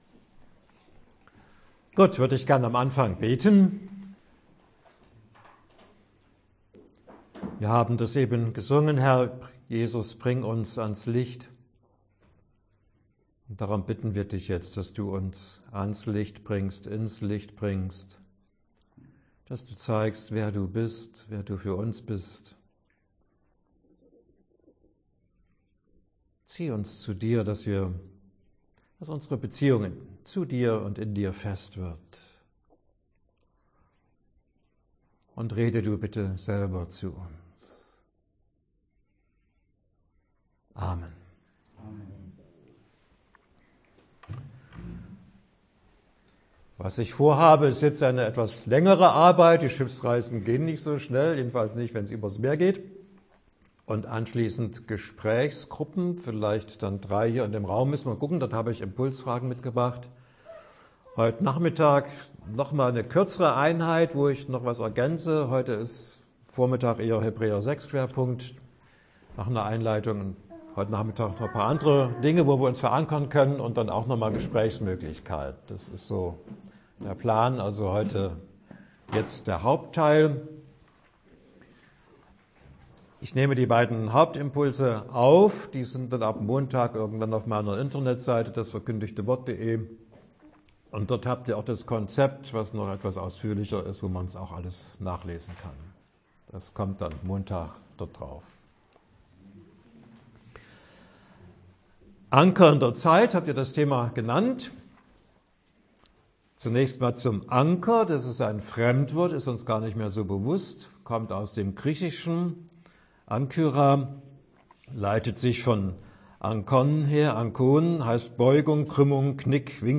Dauer des Vortrages: 45 Minuten